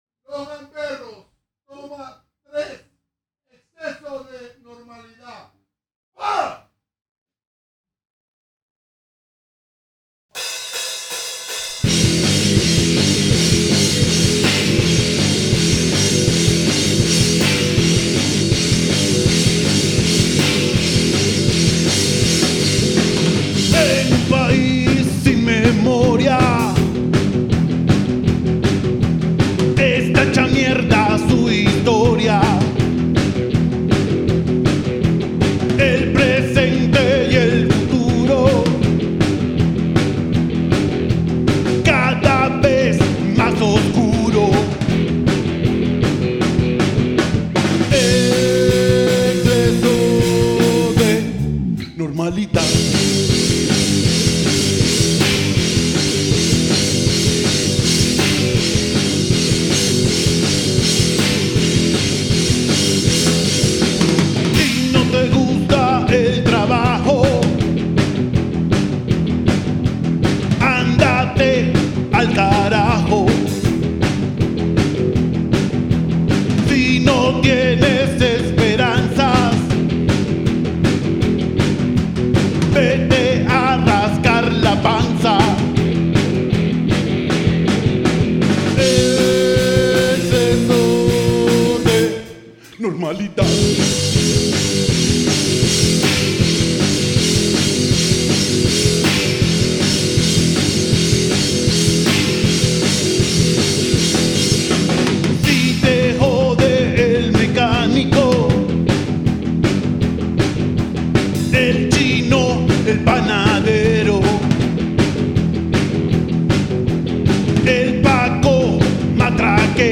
Guitarra y Voz
Bajo
Batería